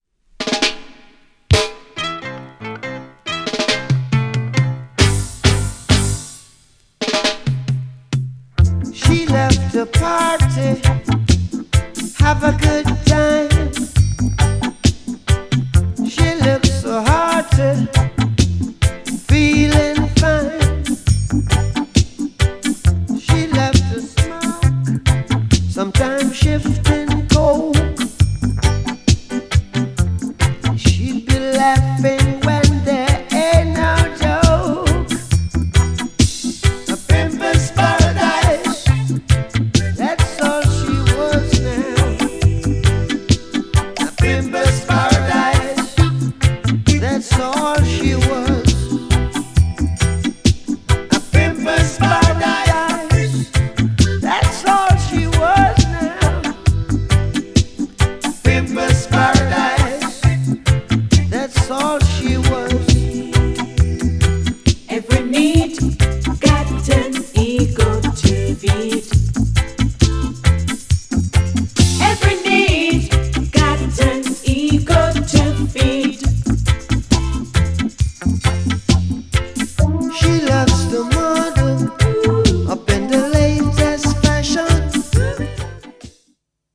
GENRE Dance Classic
BPM 71〜75BPM
ダビー # レゲエ